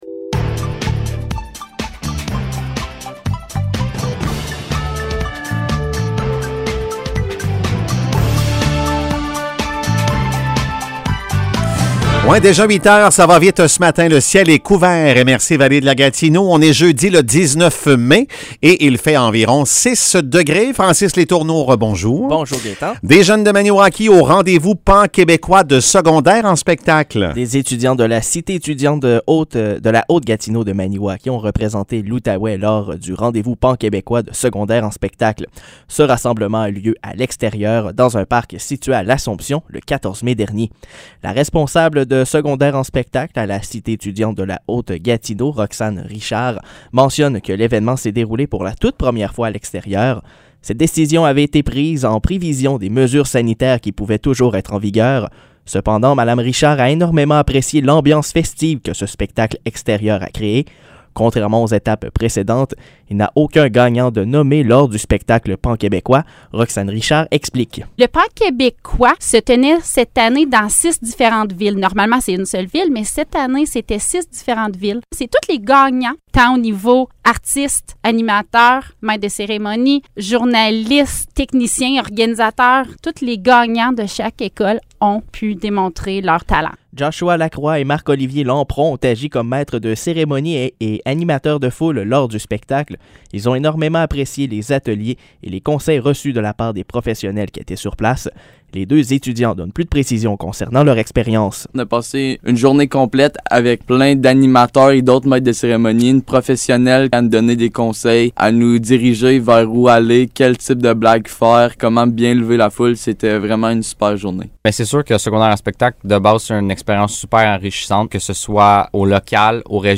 Nouvelles locales - 19 mai 2022 - 8 h